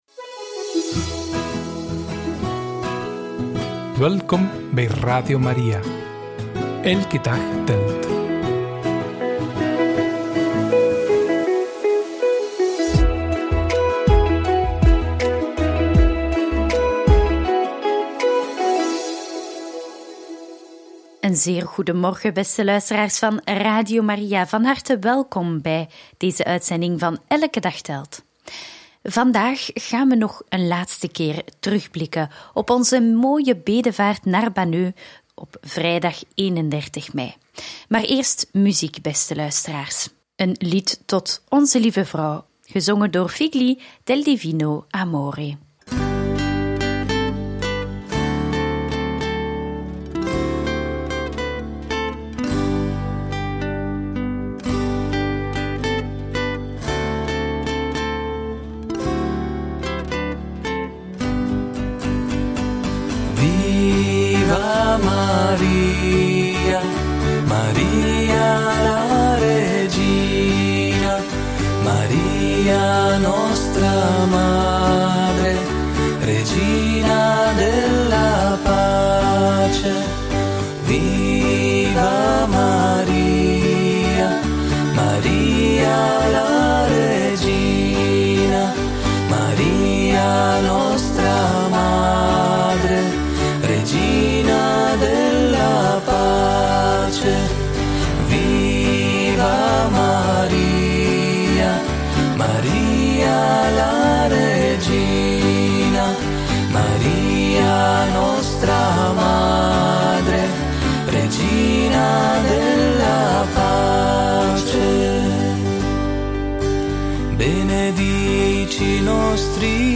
Terugblik op het rozenkransgebed vanuit Banneux op 31 mei 2024 en uitgezonden op alle Radio Maria’s wereldwijd! – Radio Maria